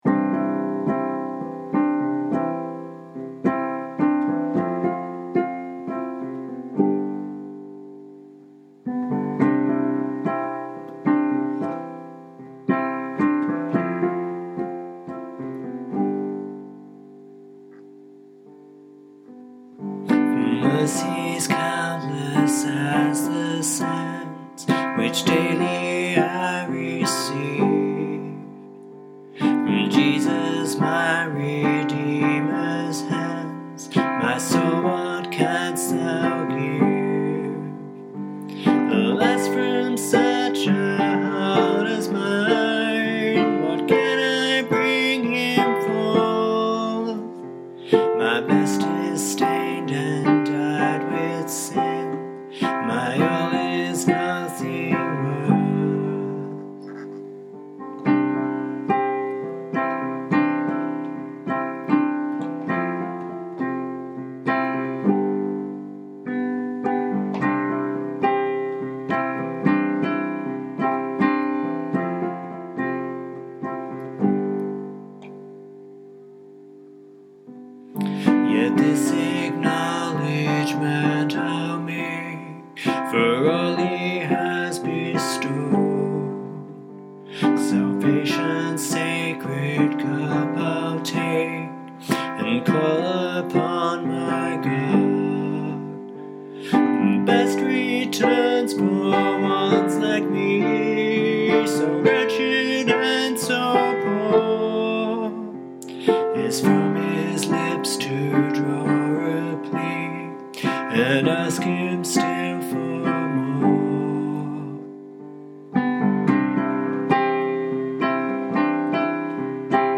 Also, I had a hard time hearing your voice over the piano, and thus could not hear the words.
There is a heavenly sweetness in it that is not always found in music these days.